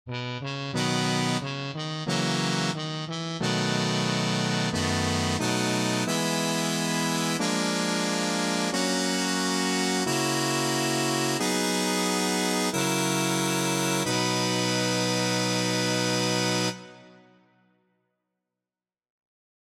Key written in: B Major
How many parts: 4
Type: Barbershop
All Parts mix: